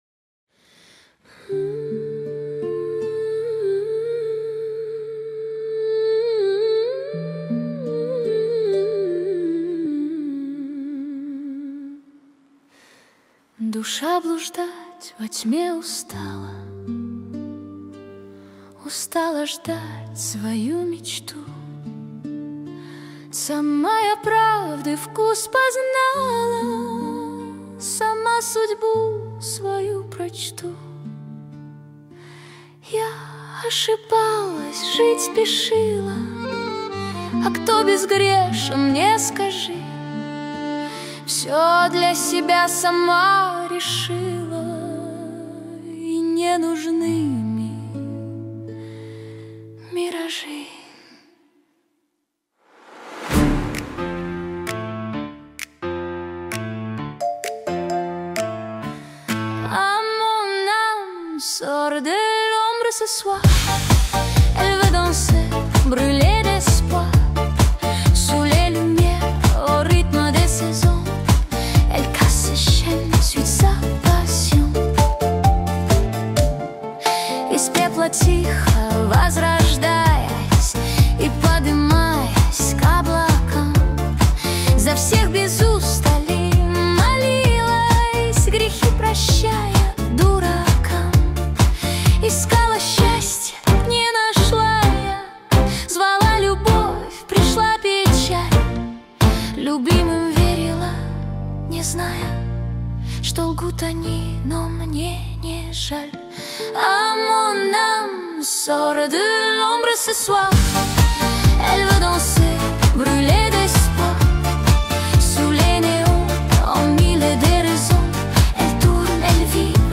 13 декабрь 2025 Русская AI музыка 74 прослушиваний